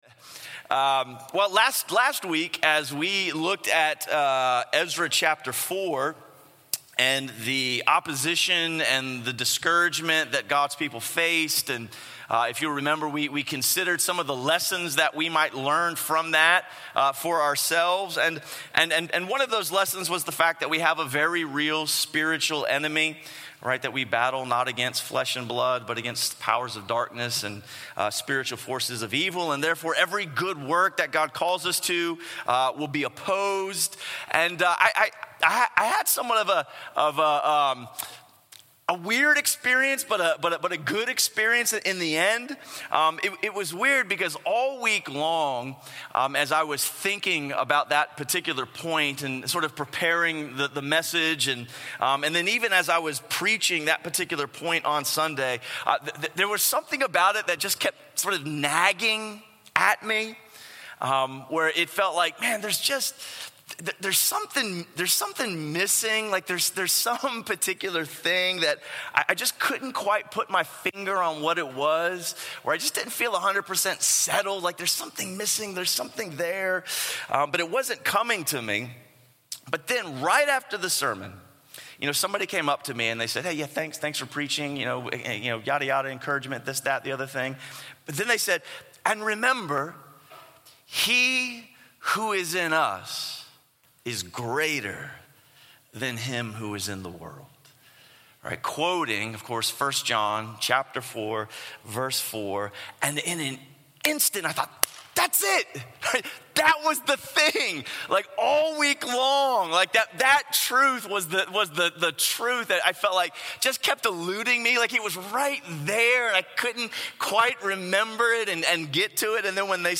A sermon series through the books of Ezra and Nehemiah.